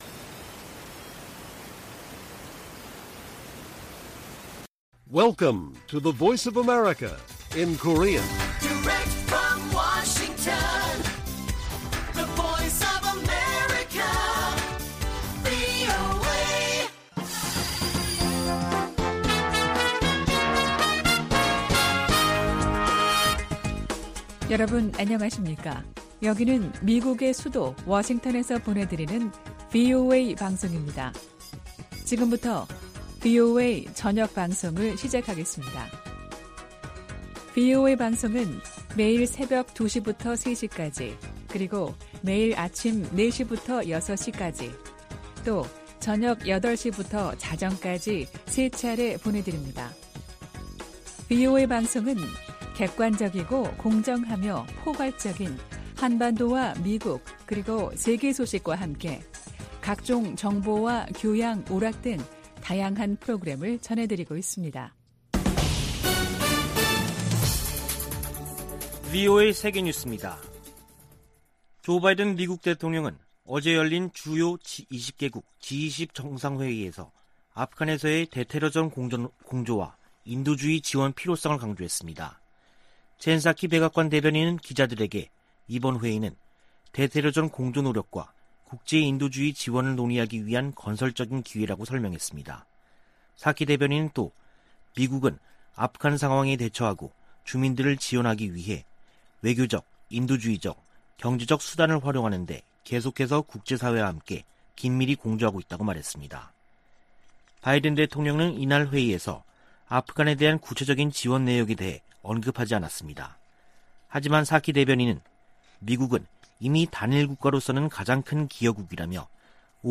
VOA 한국어 간판 뉴스 프로그램 '뉴스 투데이', 2021년 10월 13일 1부 방송입니다. 미국이 적대적이지 않다고 믿을 근거가 없다는 김정은 북한 국무위원장 발언에 대해, 미 국무부는 적대 의도가 없다고 강조했습니다. 제이크 설리번 미 국가안보좌관이 워싱턴에서 서훈 한국 국가안보실장과 북한 문제 등을 논의했습니다. 유엔 안보리 대북제재위원회로부터 제재 면제를 승인 받은 국제 지원 물품들이 북한 반입을 위해 대기 상태입니다.